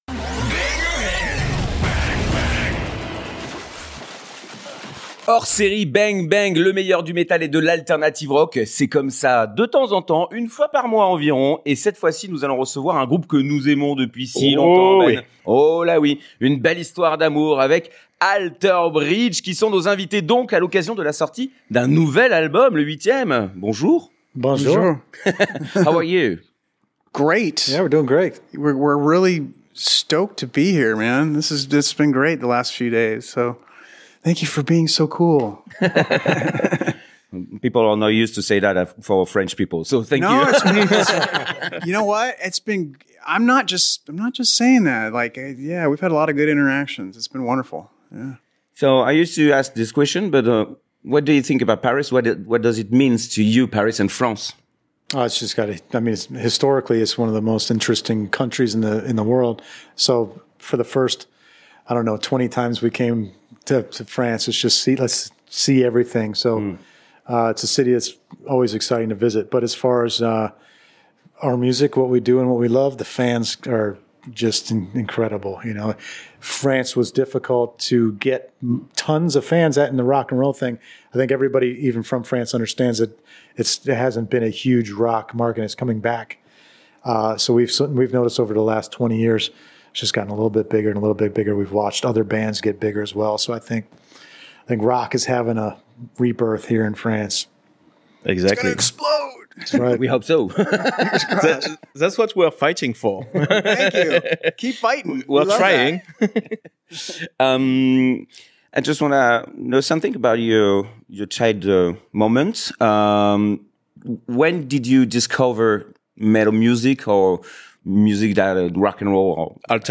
BANG! BANG! Interview : ALTER BRIDGE - RSTLSS